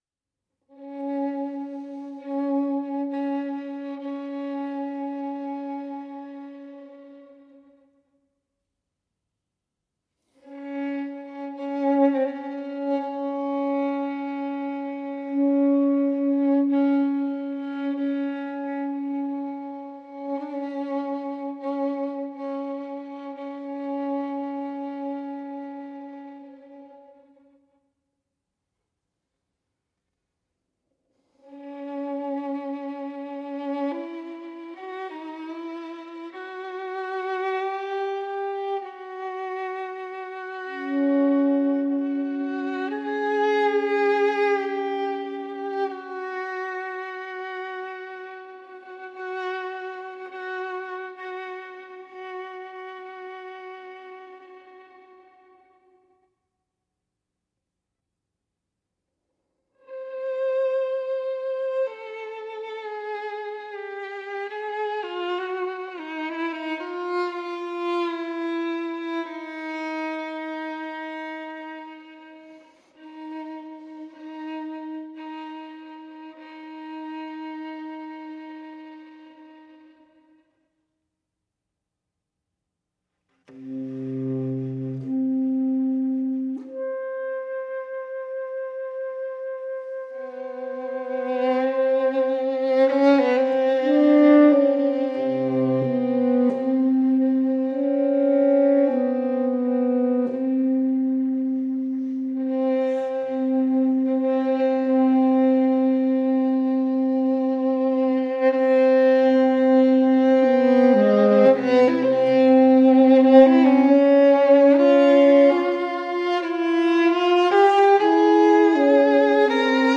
tenor saxophone